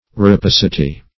Rapacity \Ra*pac"i*ty\ (r[.a]*p[a^]s"[i^]*t[y^]), n. [L.